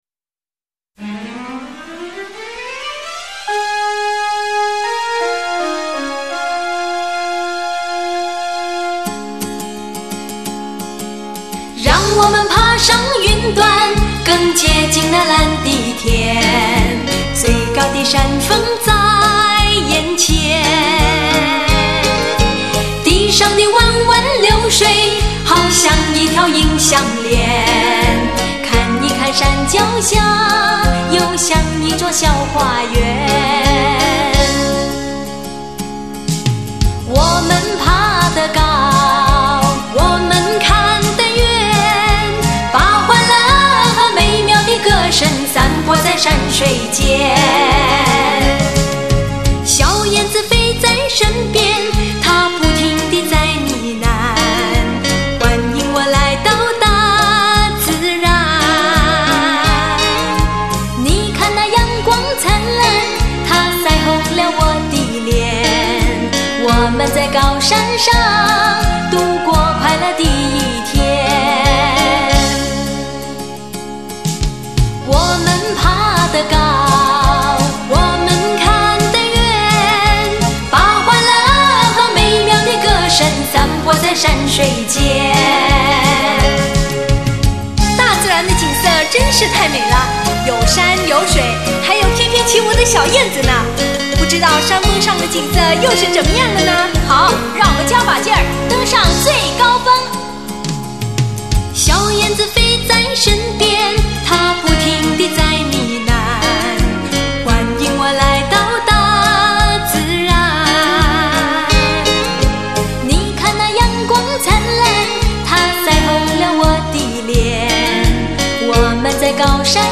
双人女子组合
情歌加入现代的东方说唱韵律。